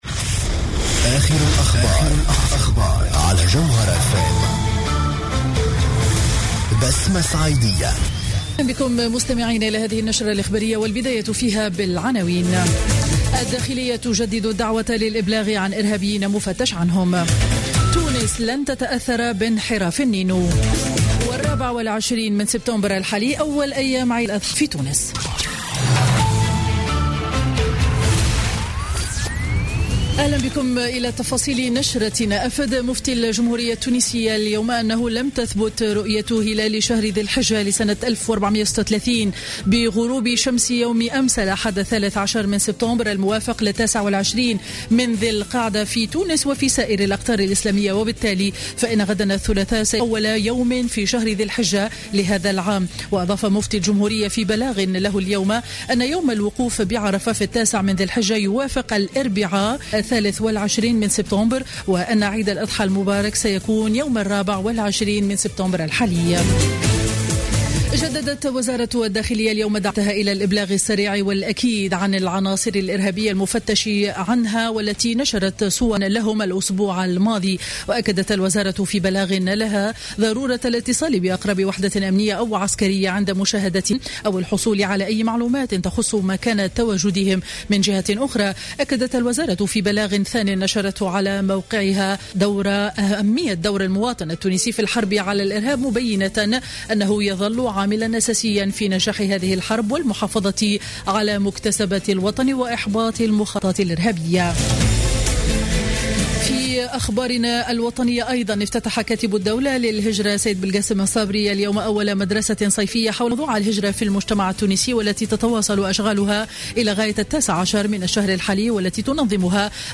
نشرة أخبار منتصف النهار ليوم الإثنين 14 سبتمبر 2015